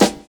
NY 10 SD.wav